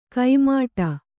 ♪ kai māṭa